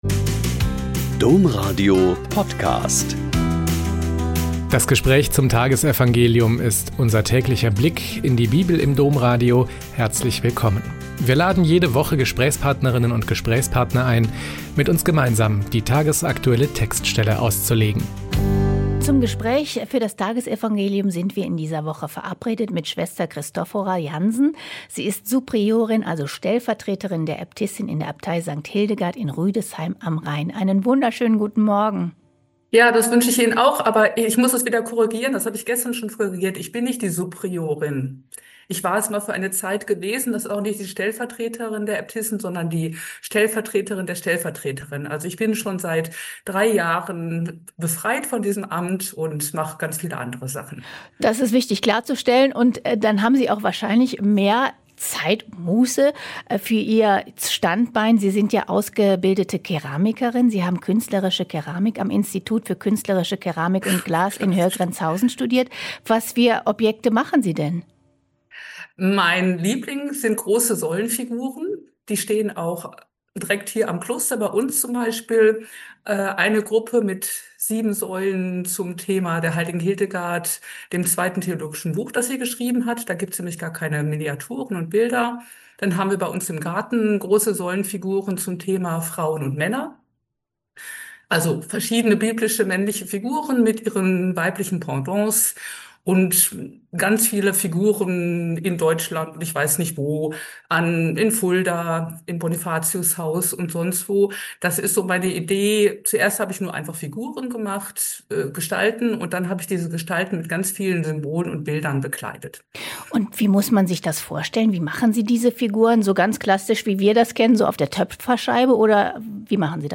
Mt 1,1-17 - Gespräch